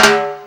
44_21_tom.wav